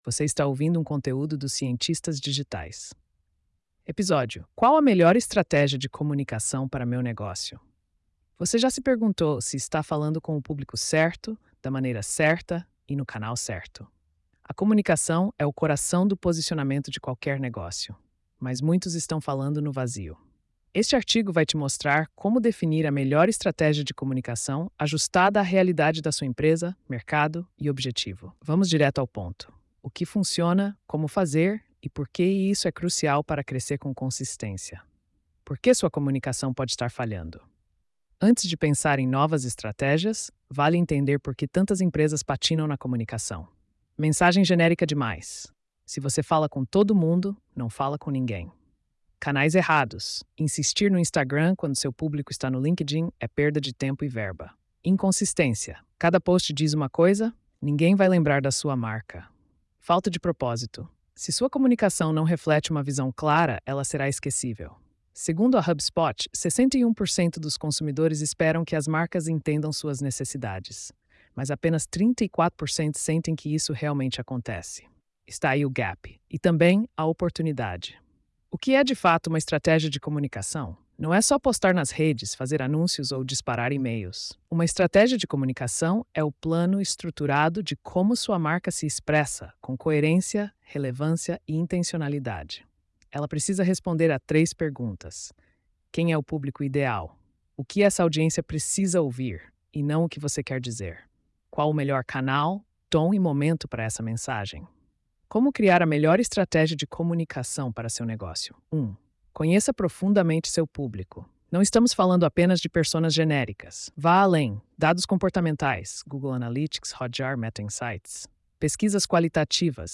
post-3093-tts.mp3